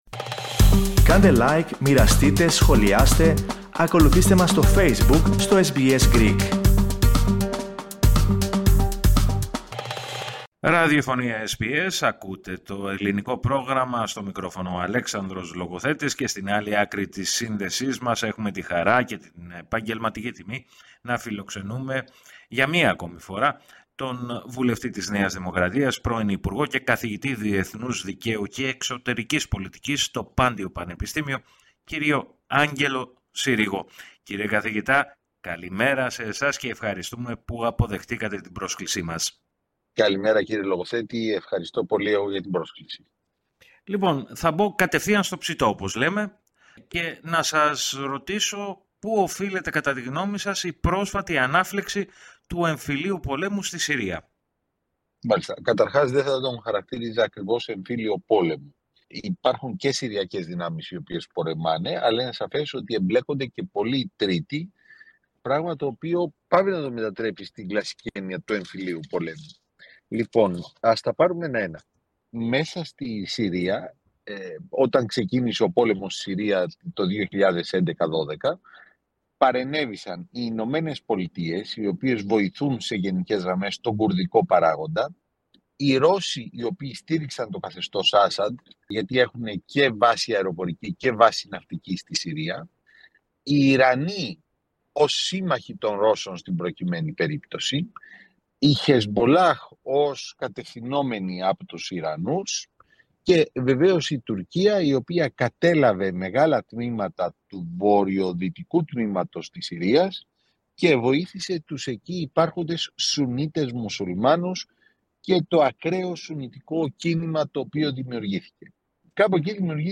Η μέχρι πρότινος παγωμένη σύρραξη στη Συρία, έχει αναθερμανθεί, προκαλώντας πρόσθετες ανησυχίες στη διεθνή κοινότητα. Με αυτήν την αφορμή, μίλησε στο Ελληνικό Πρόγραμμα της ραδιοφωνίας SBS, ο βουλευτής της ΝΔ, πρώην υπουργός, και καθηγητής Διεθνούς Δικαίου και Εξωτερικής Πολιτικής, στο Πάντειο Πανεπιστήμιο, Άγγελος Συρίγος.